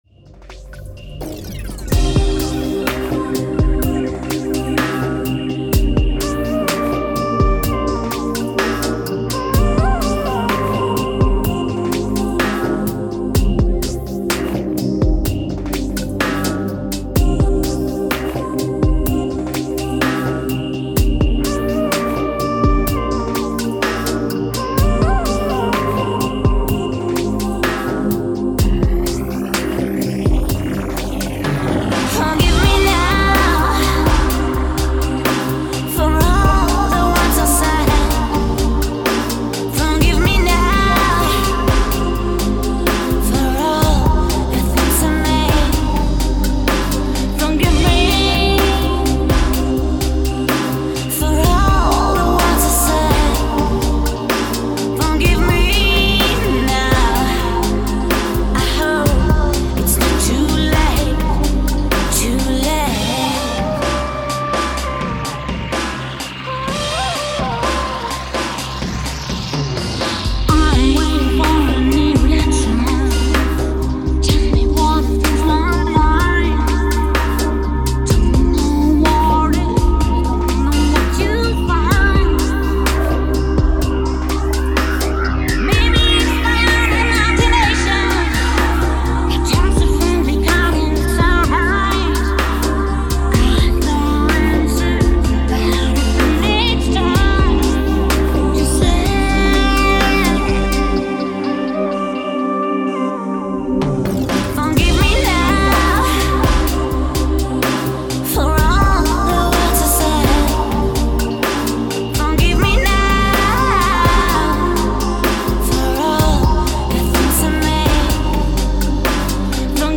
• Категория:Успокаивающая музыка
• Качество MP3: 44100 Hz, 320 kbps, 16 bit, Stereo